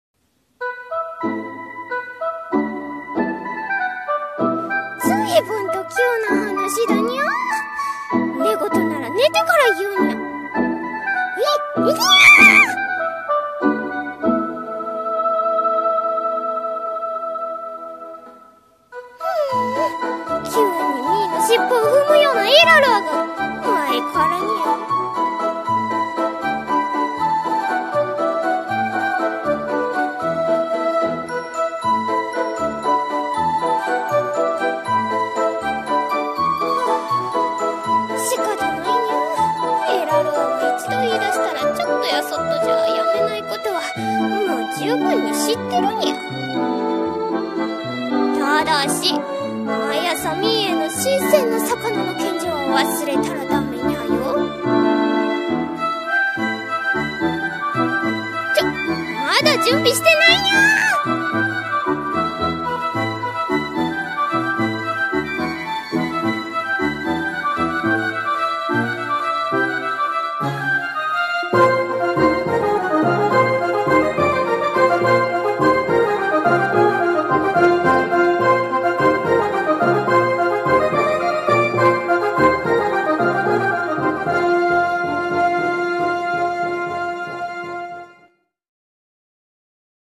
声劇】魔女と猫と旅立ちの朝